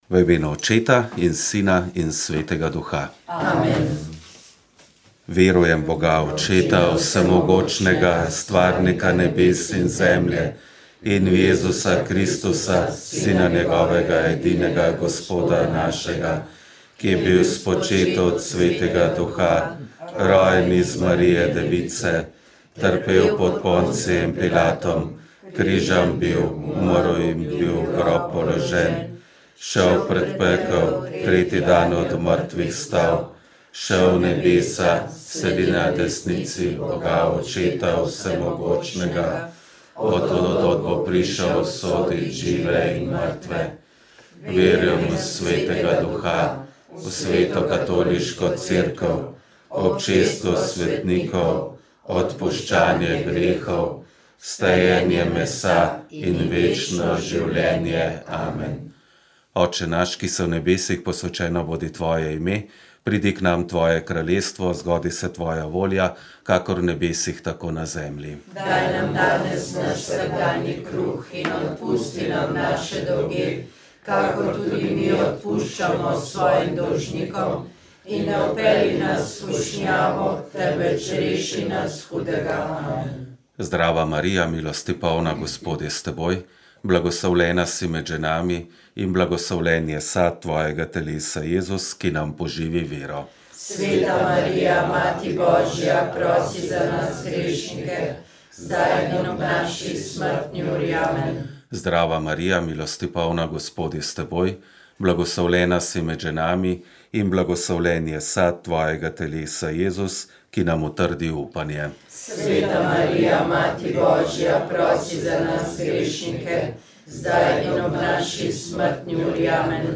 Rožni venec